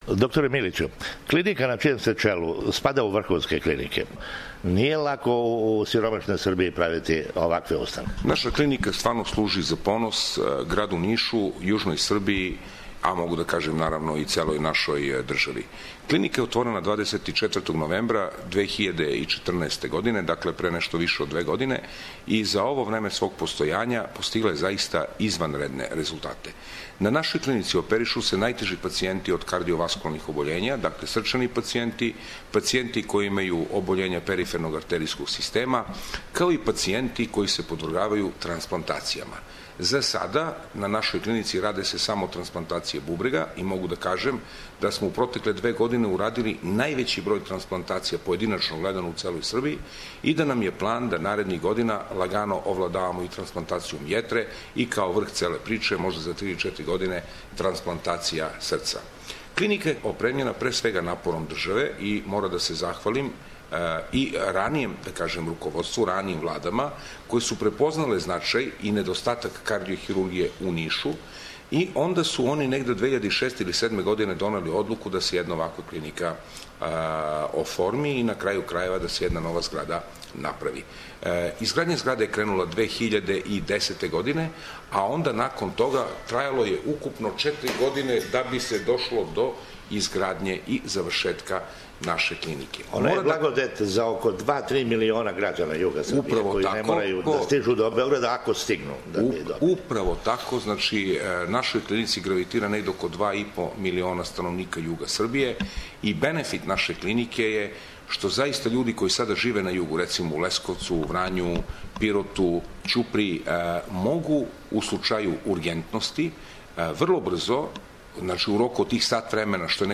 У ексклузивном интервјуу